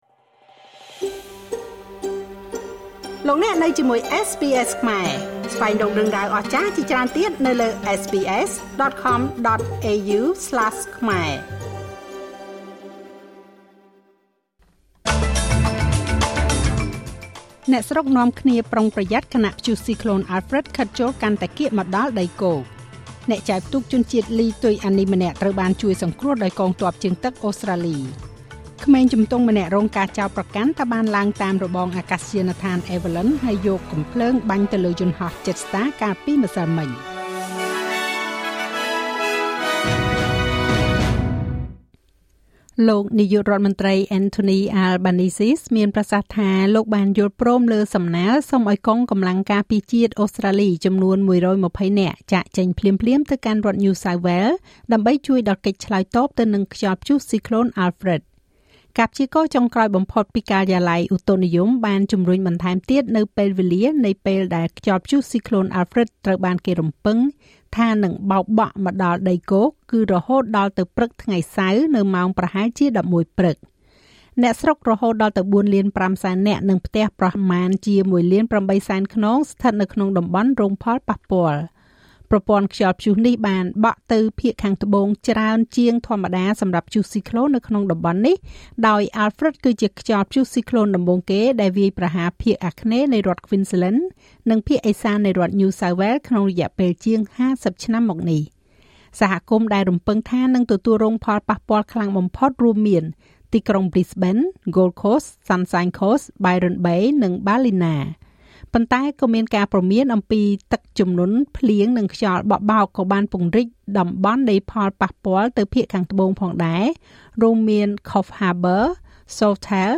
នាទីព័ត៌មានរបស់SBSខ្មែរ សម្រាប់ថ្ងៃសុក្រ ទី7 ខែមីនា ឆ្នាំ២០២៥